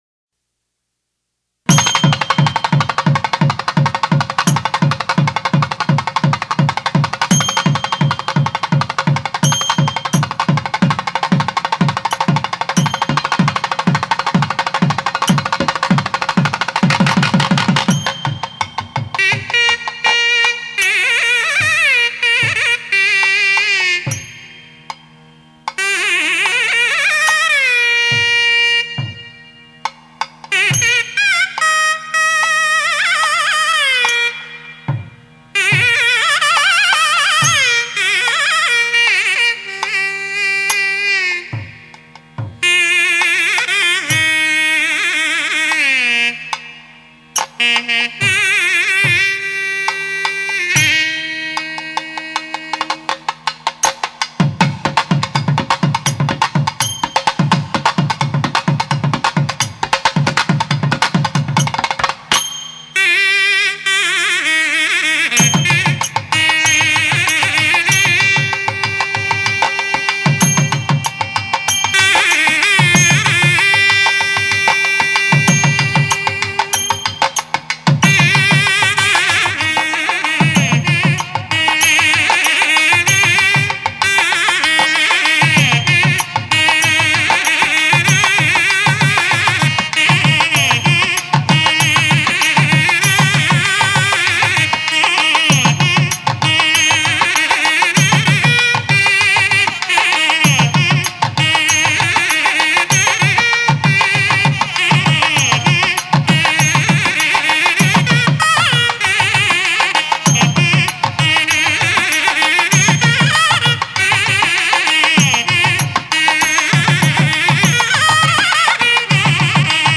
Naathasvaram